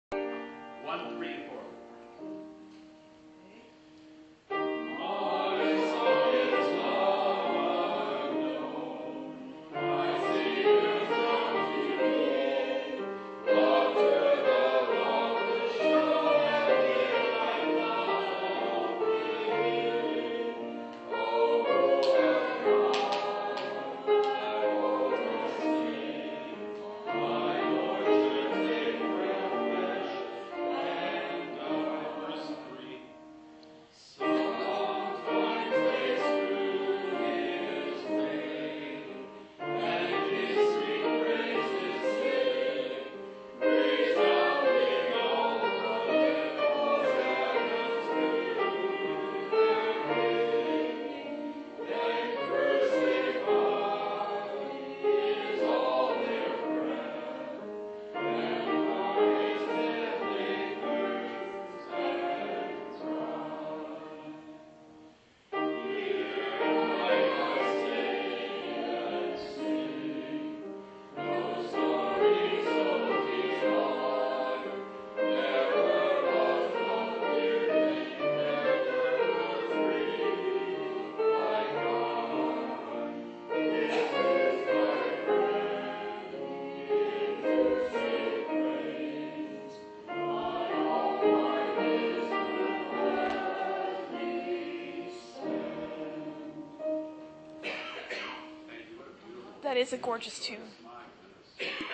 Can anyone tell me what this lovely hymn tune is? I came into the hymn sing as it was starting, couldn't hear the opening line and never found out what the hymn was.
:-) I realize the singing isn't very, um, professional, but the people are lovely, and I enjoy being with them and singing with them when we sing something I know.